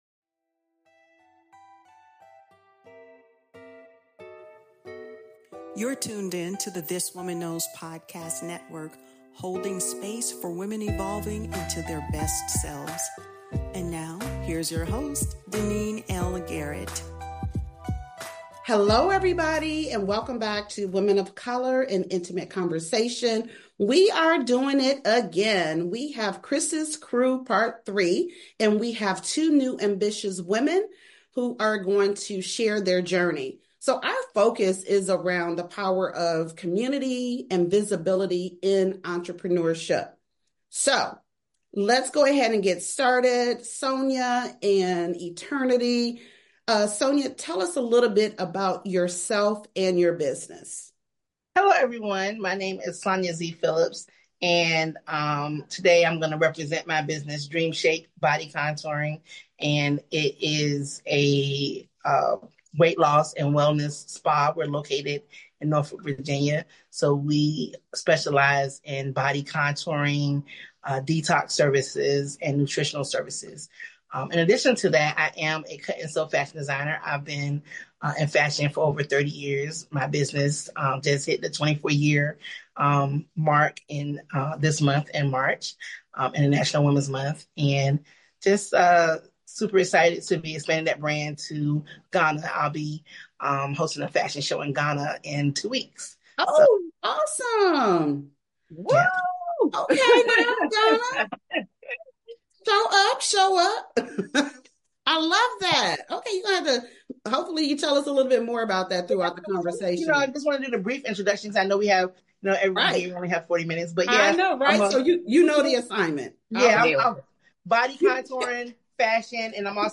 For women of color entrepreneurs, community isn’t a luxury—it’s a lifeline. Whether it’s finding your tribe, building your brand, or breaking through mindset blocks, this conversation affirms that we are stronger, louder, and more successful together.